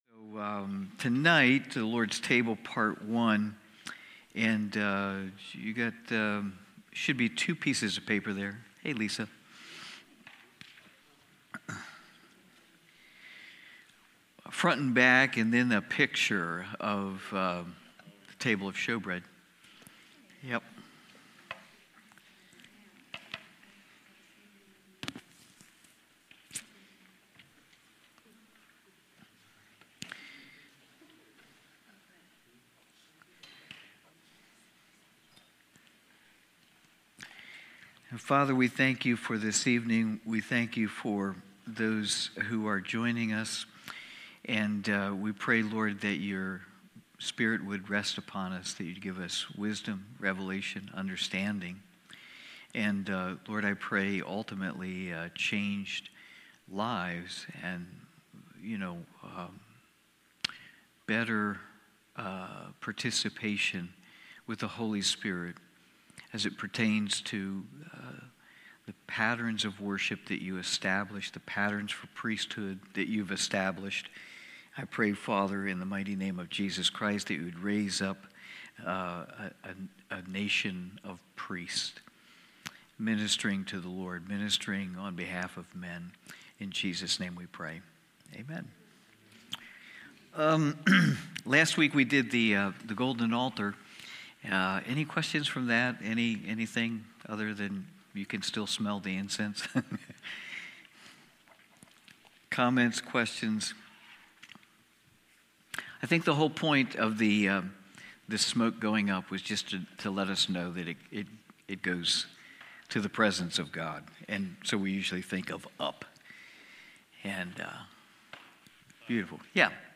Wednesday evening Bible study.